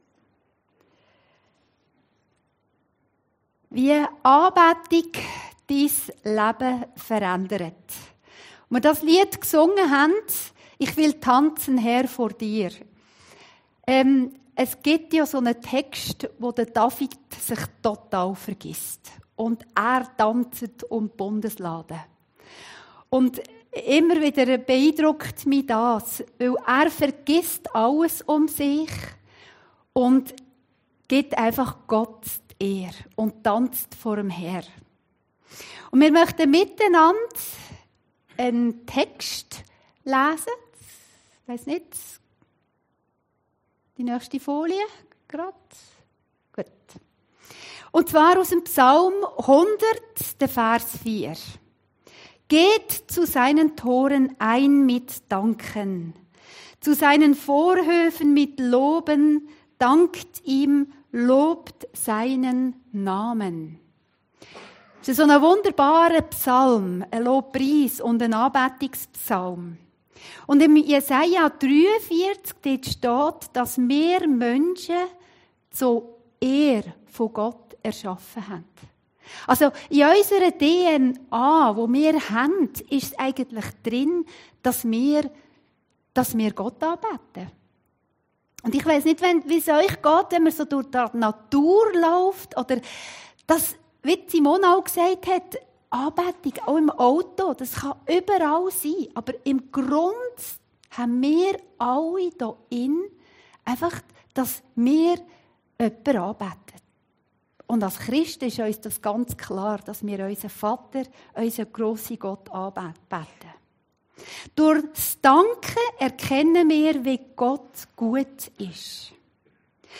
Predigten Heilsarmee Aargau Süd – Wie Anbetung und Worship Dein Leben Verändert